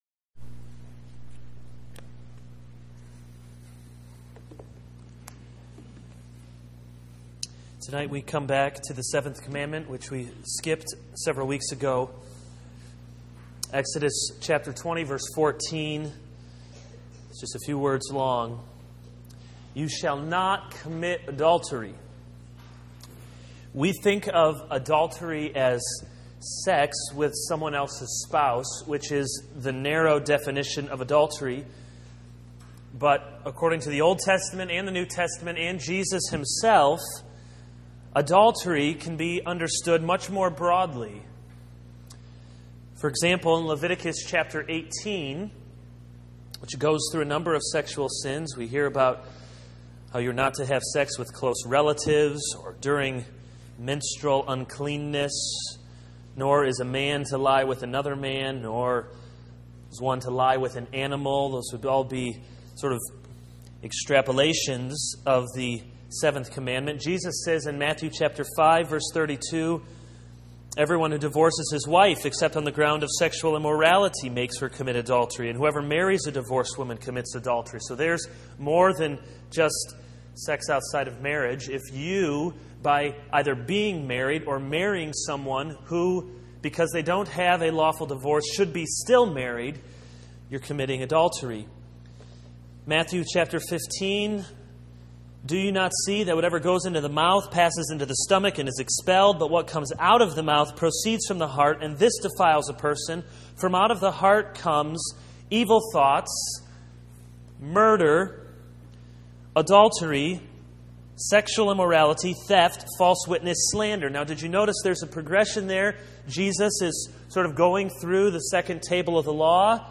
This is a sermon on Exodus 20:1-17.